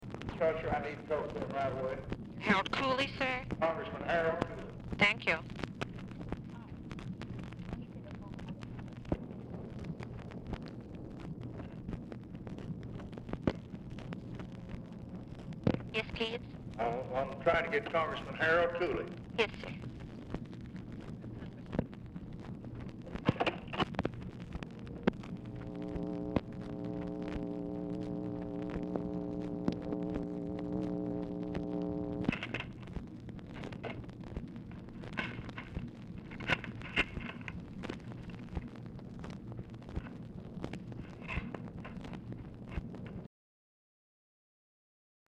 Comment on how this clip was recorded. Format Dictation belt Location Of Speaker 1 Oval Office or unknown location